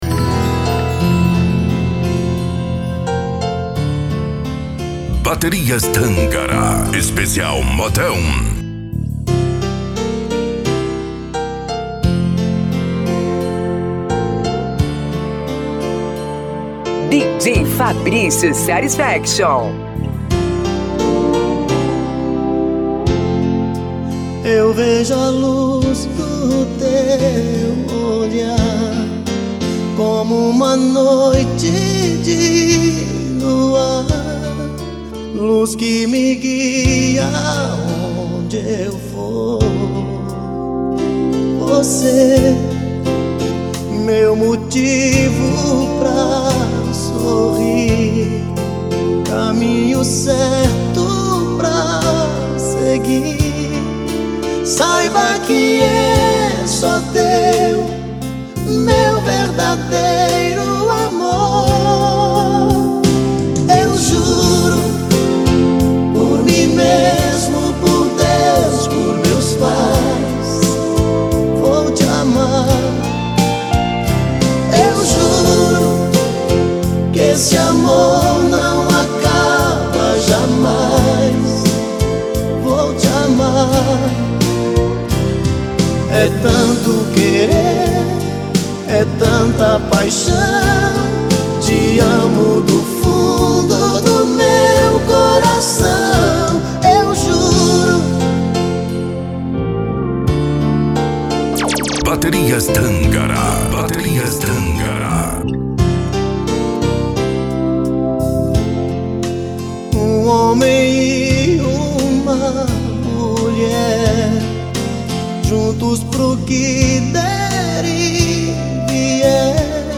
Modao